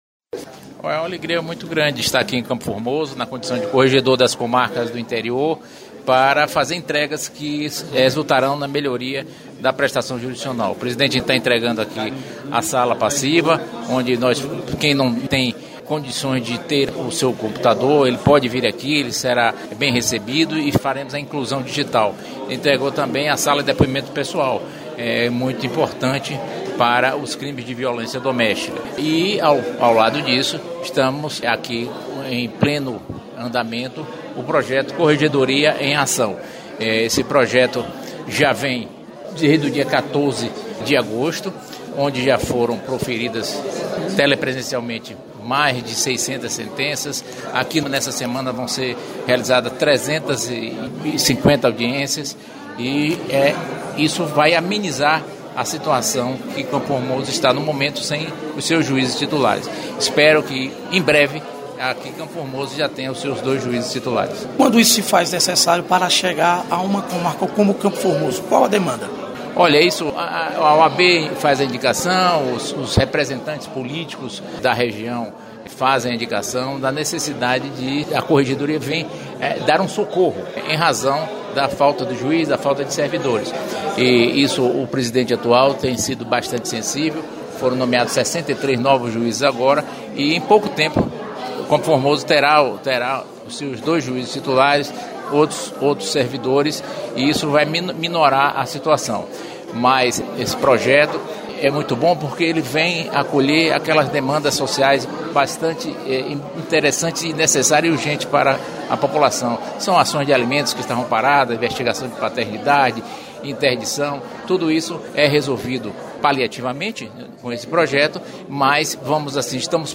Desembargador Dr. Jathay Júnior, falando da importância da corregedoria chegar a Campo Formoso